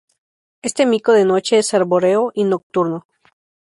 /ˈmiko/